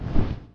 挥动2zth070521.wav
通用动作/01人物/03武术动作类/挥动2zth070521.wav
• 声道 單聲道 (1ch)